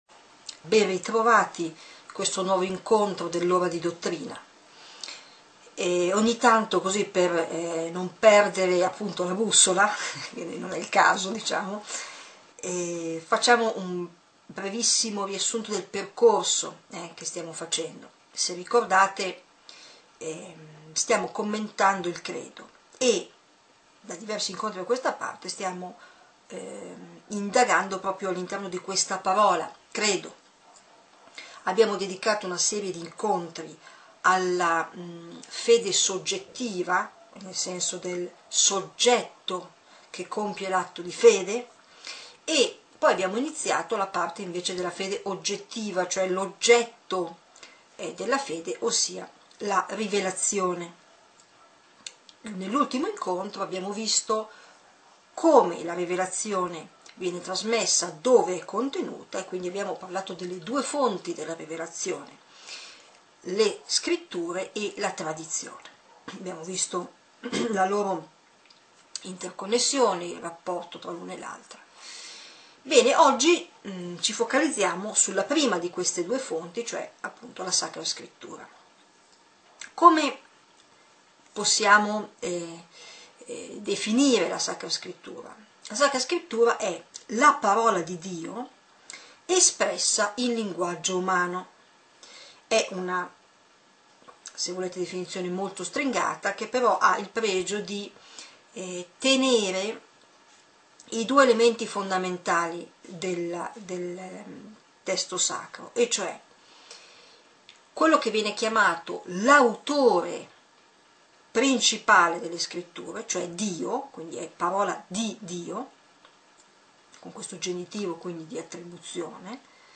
Lora-di-dottrina_09LISPIRAZIONE-DELLA-SACRA-SCRITTURA-Lezione-9-del-Catechismo.mp3